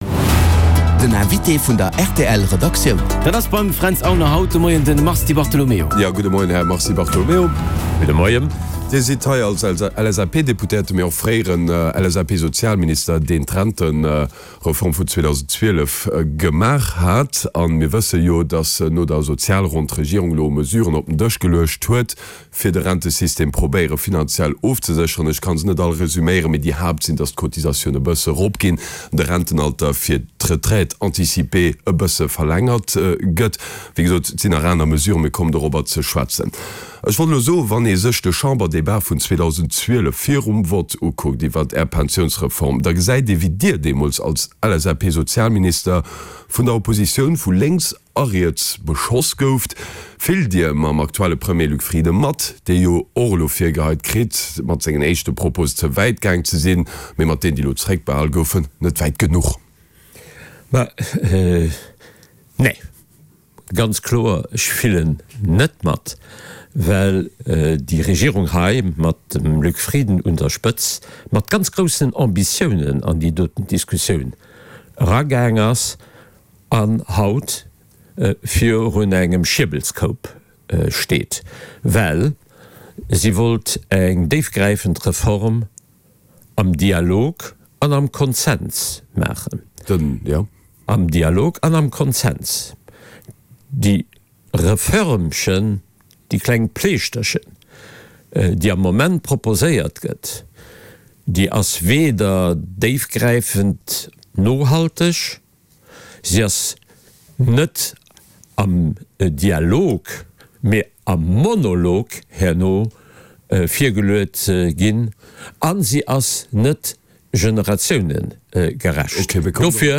Den Hannergrondinterview mat Vertrieder aus Politik an Zivilgesellschaft